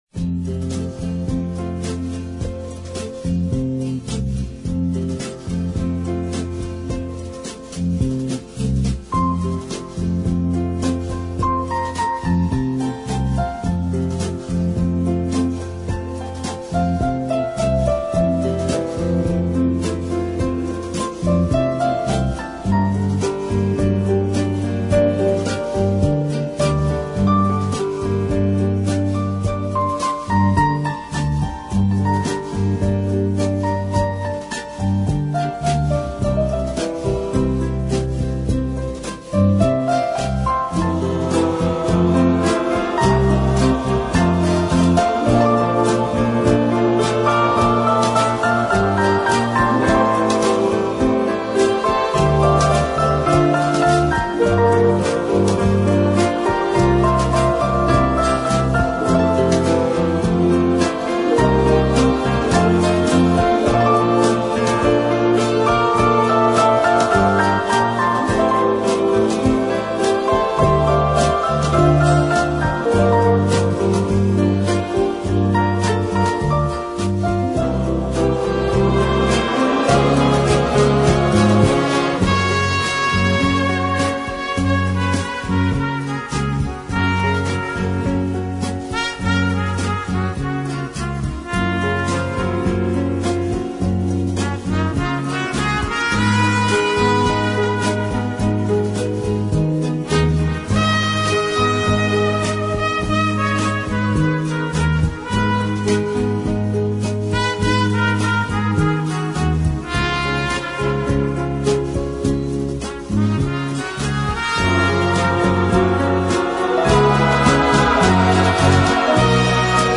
Instrumental Para Ouvir: Clik na Musica.